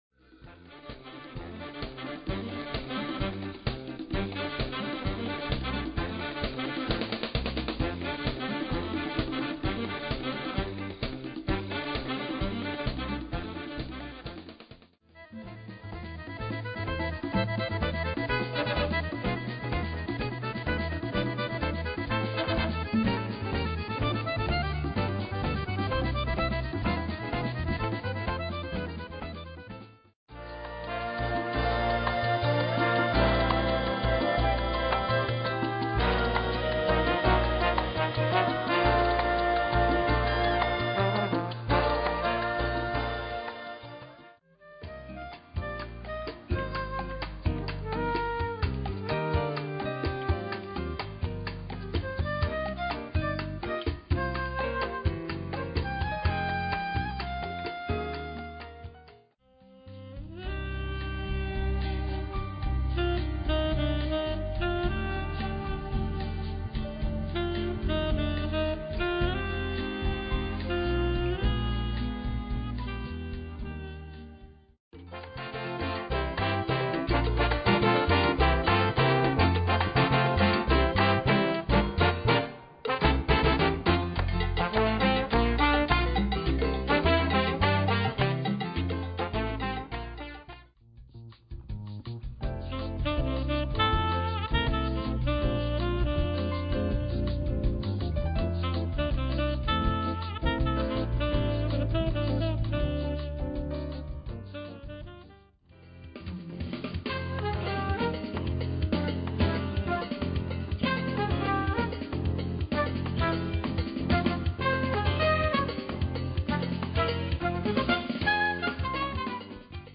Latin Dance